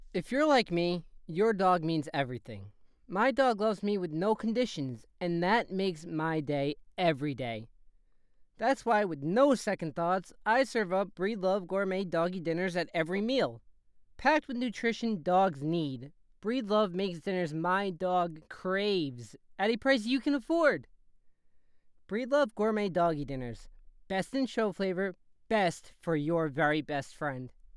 commercial sample
high tone. medium tone, new yorker,
Ranging from mid-high pitched tones, neardy, and nasally.
Microphone Rode NT1